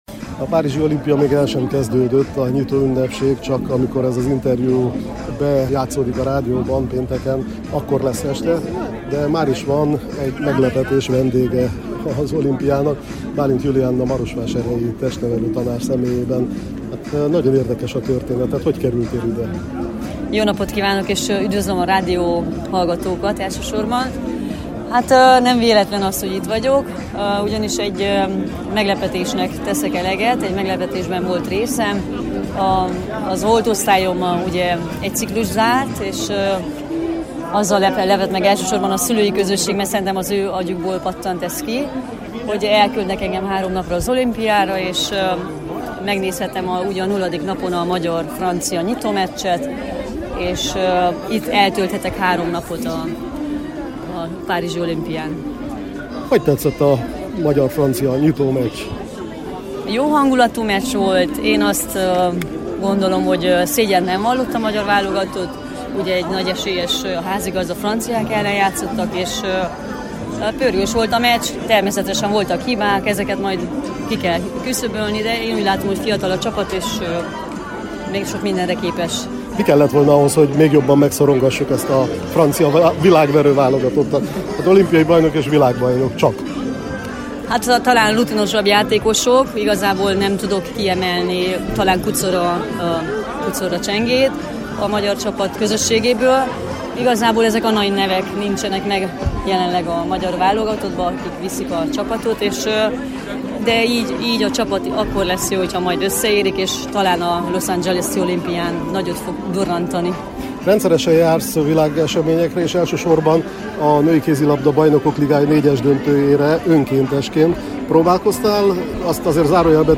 Párizsban, a kézilabda mérkőzéseknek helyet adó aréna előtt: